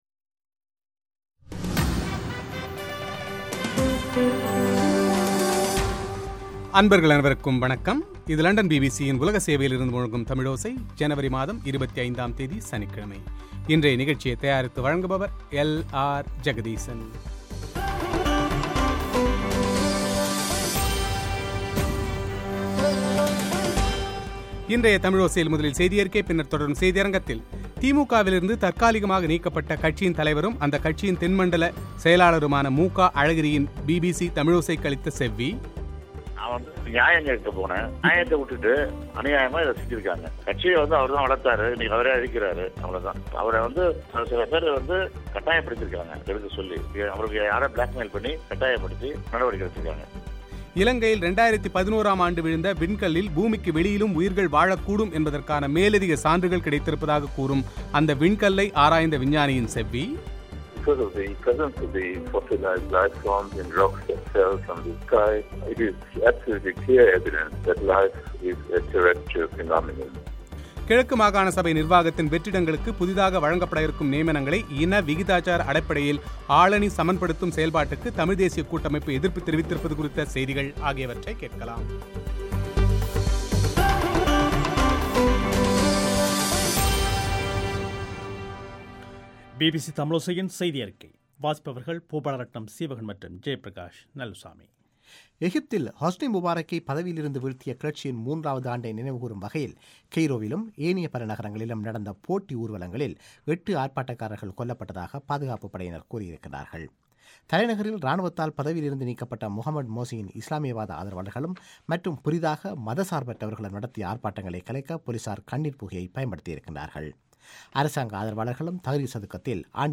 திமுகவிலிருந்து தற்காலிகமாக நீக்கப்பட்ட, அந்த கட்சியின் தலைவர் மு கருணாநிதியின் மகனும் அந்த கட்சியின் தென்மண்டல செயலாளருமான மு க அழகிரி பிபிசி தமிழோசைக்கு அளித்த பிரத்யேக செவ்வி;